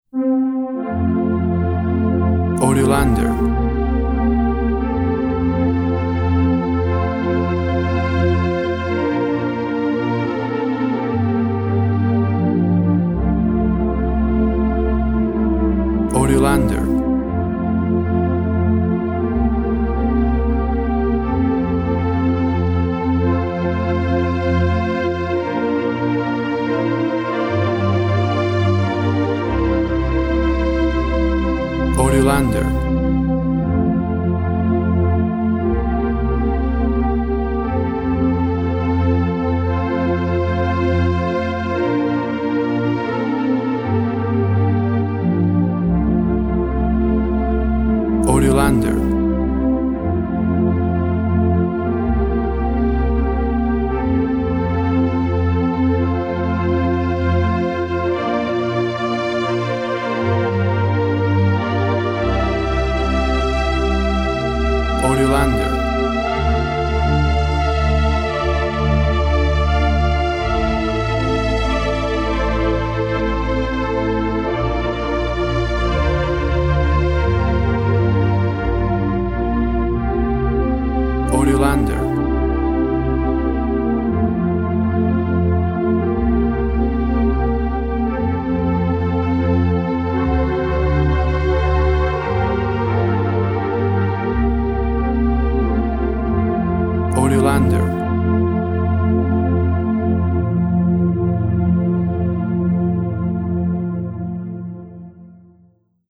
Synth strings create a solemn and hopeful mood.
Tempo (BPM) 72